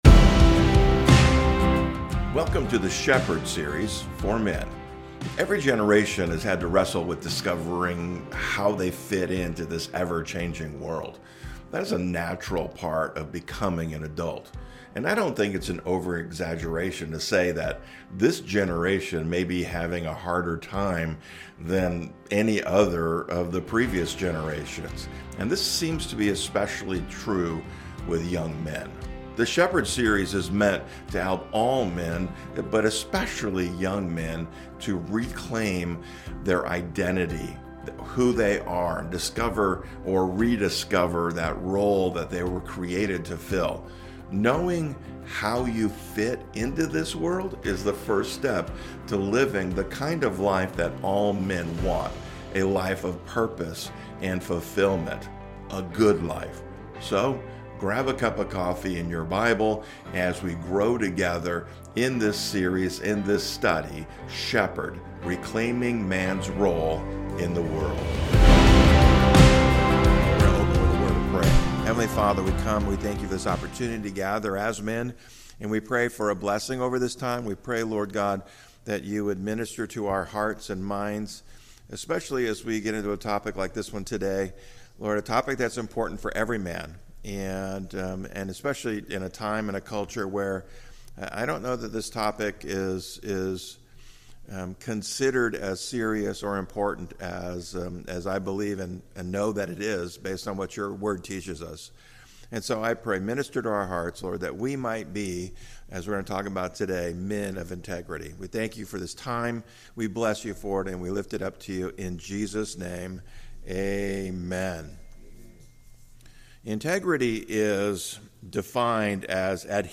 STRENGTH TRAINING Men's Breakfast - Calvary Chapel French Valley
SHEPHERD is a series of messages for men.